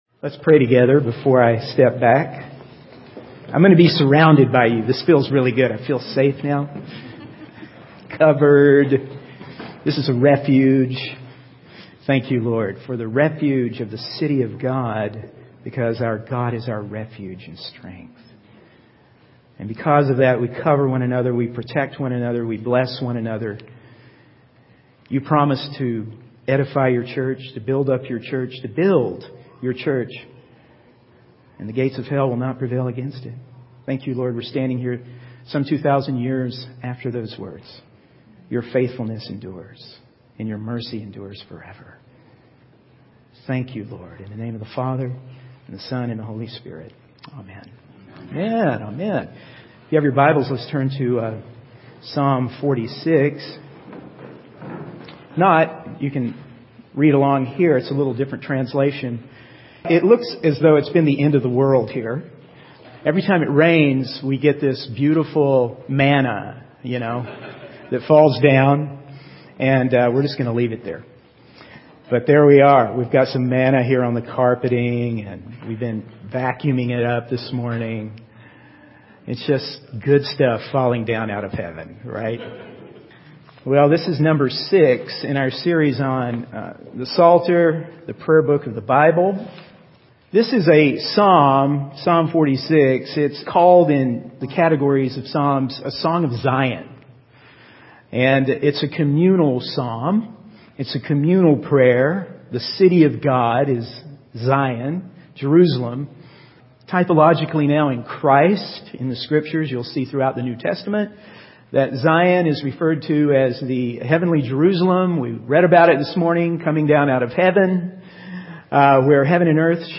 In this sermon, the speaker emphasizes the power and victory found in the word of God.